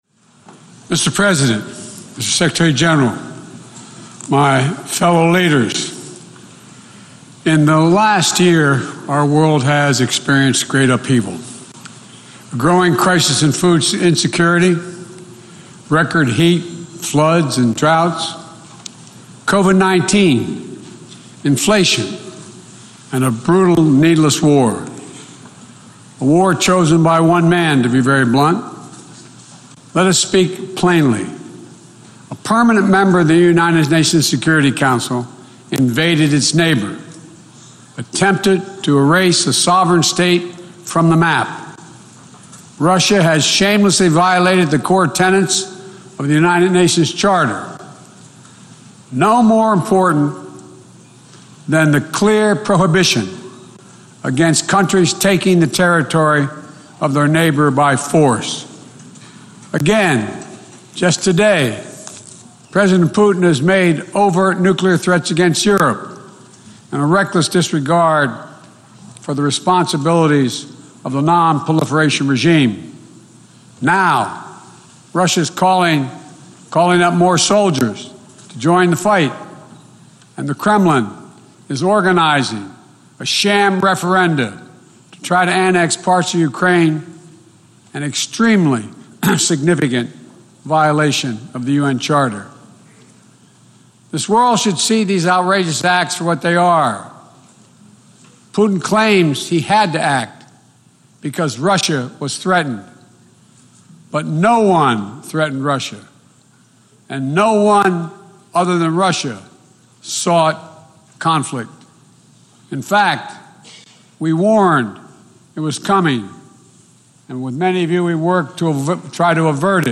Joe Biden - Address to the 77th Session of the United Nations General Assembly (text-audio-video)